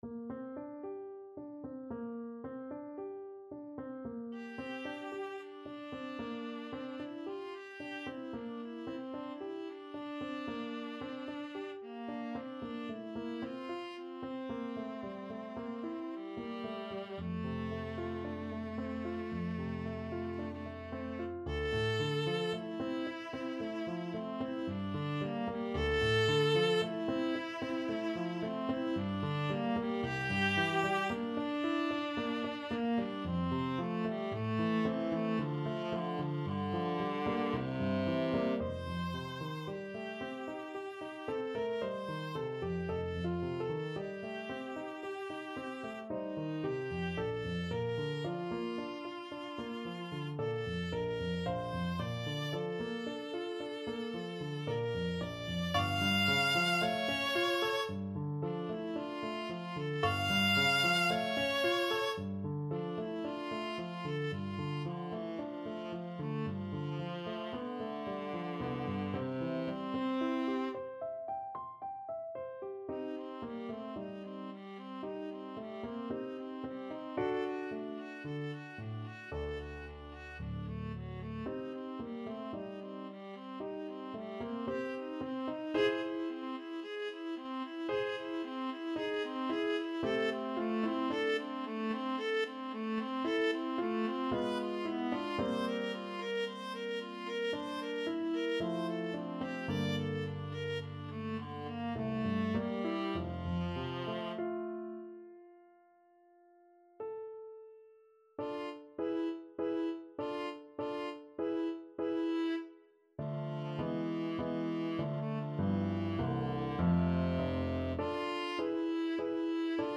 ViolaPiano
4/4 (View more 4/4 Music)
Viola  (View more Intermediate Viola Music)
Classical (View more Classical Viola Music)